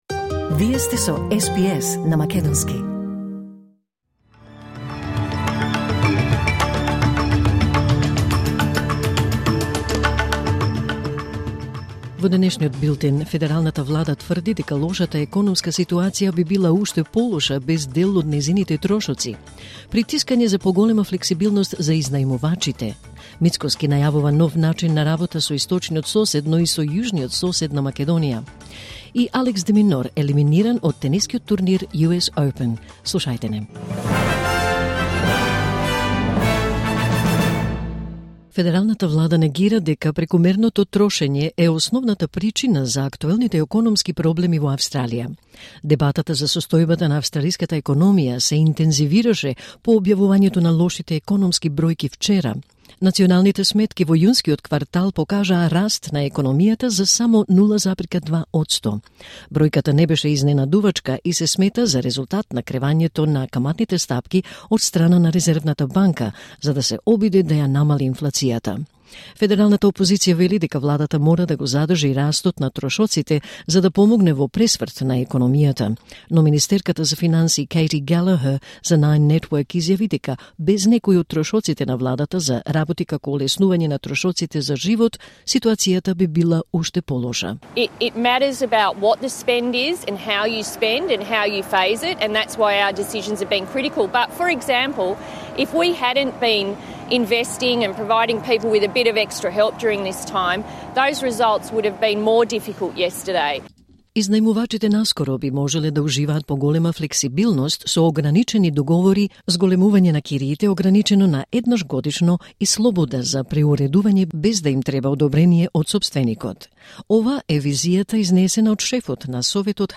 Вести на СБС на македонски 5 септември 2024